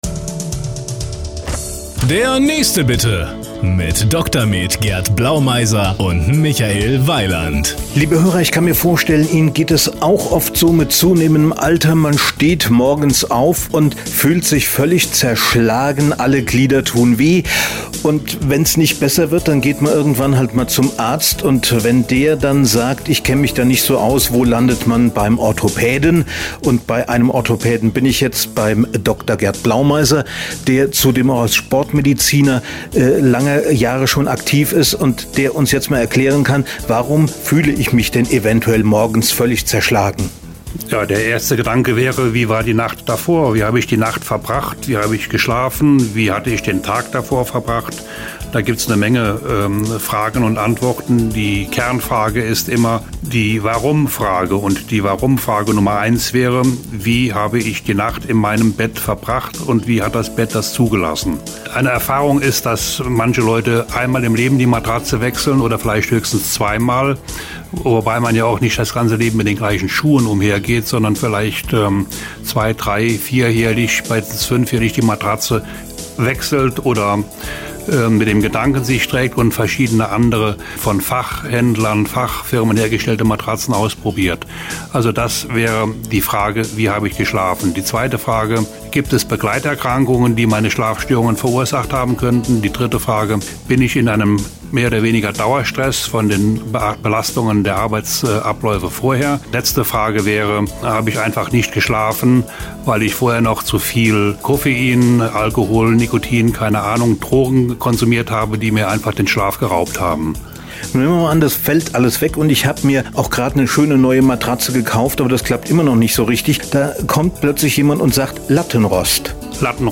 Radio- Beiträge und Interviews zu Auto Motor Wirtschaft Gesundheit Computer Software